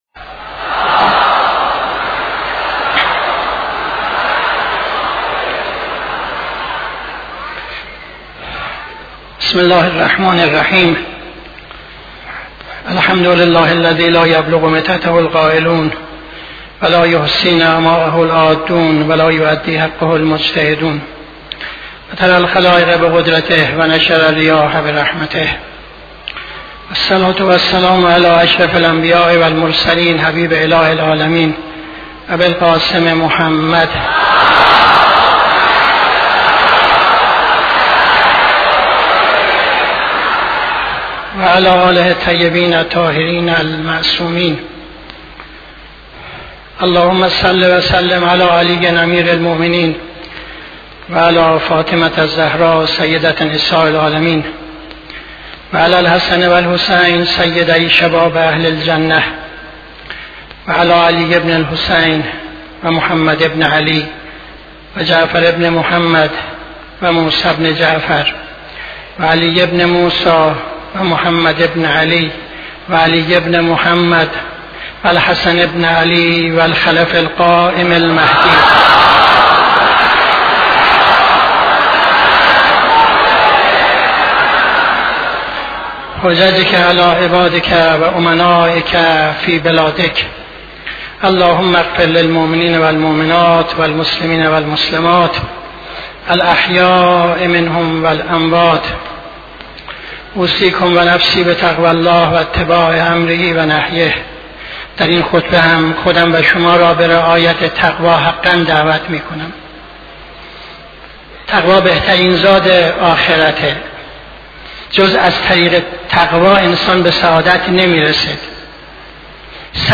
خطبه دوم نماز جمعه 27-11-74